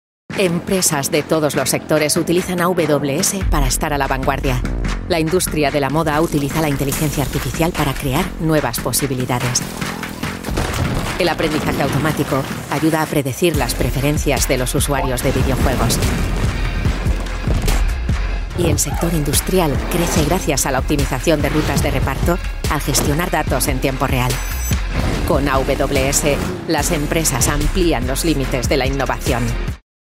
Female
She is able to record bass and high tones, corporative and natural narrations, resulting very versatile.
Words that describe my voice are elegant voice, compelling voice, corporative voice.